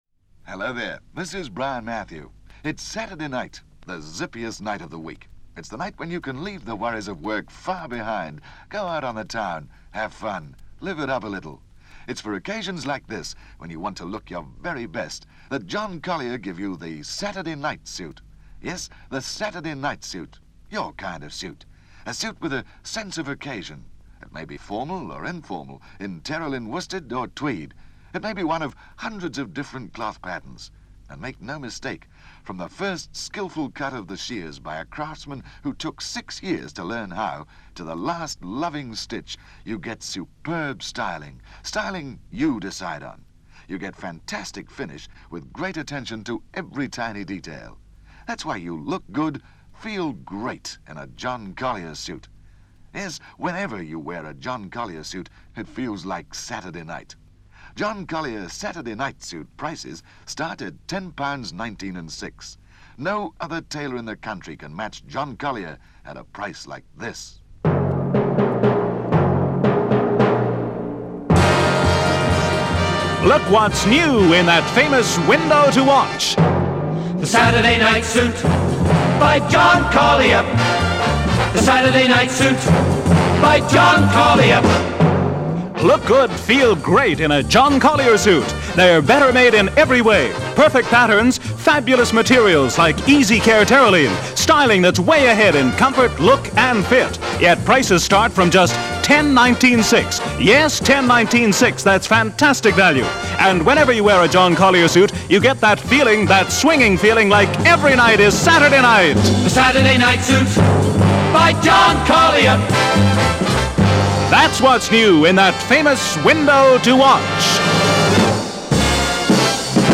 suitably swinging lounge number